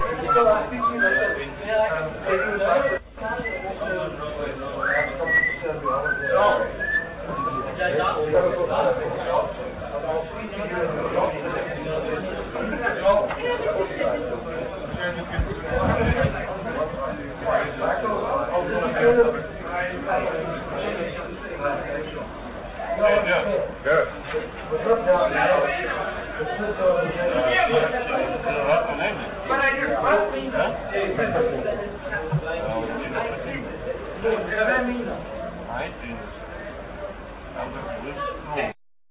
Maun 65 Charter